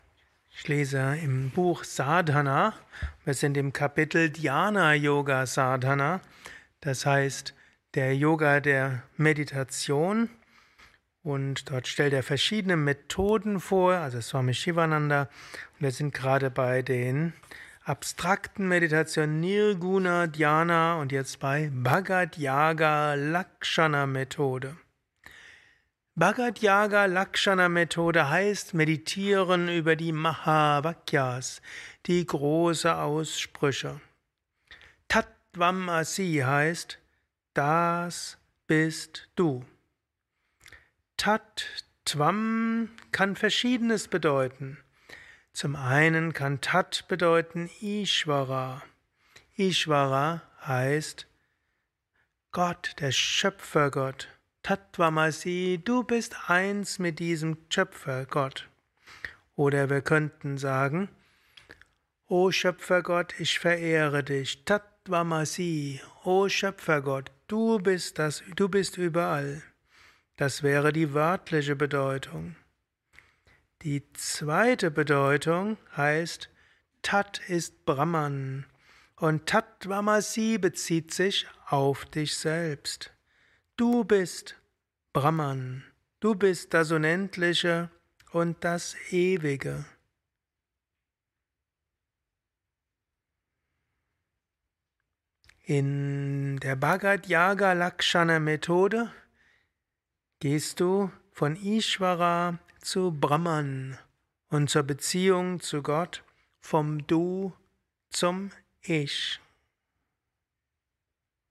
gehalten nach einer Meditation im Yoga Vidya Ashram Bad Meinberg.